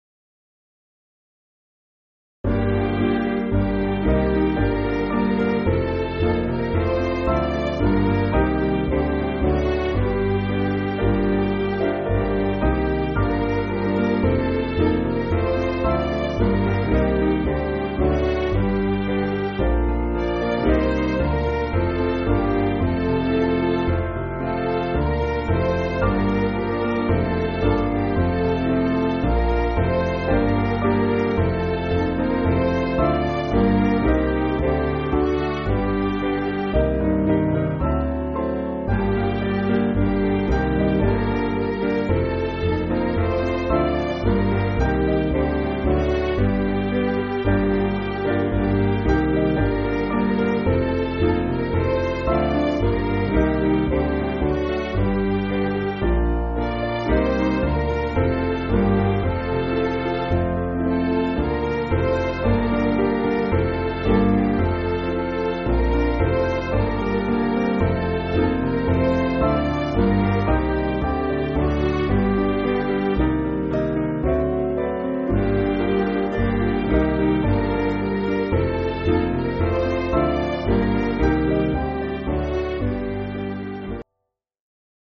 Piano & Instrumental
(CM)   6/Gm